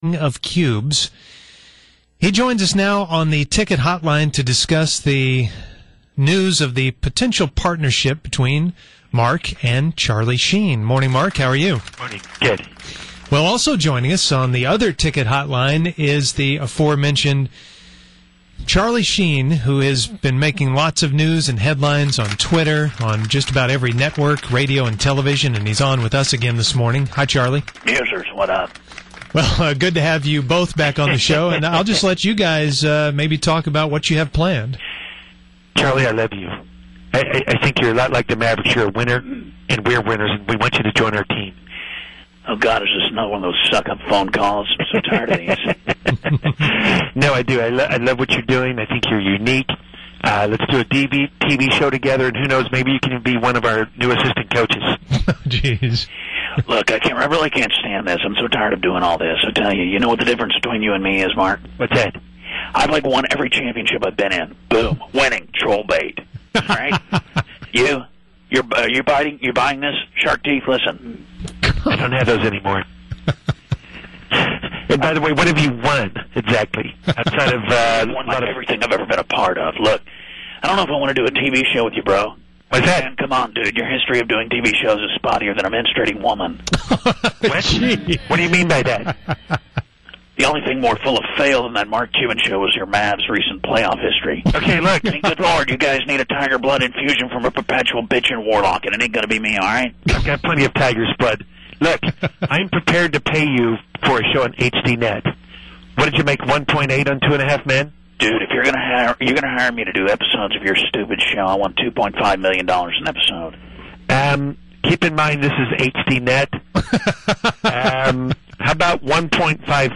Fake Mark Cuban & Fake Charlie Sheen Talk
Musers had fake Mark Cuban and Charlie Sheen on to discuss a deal with Charlie Sheen having a show on HDNET.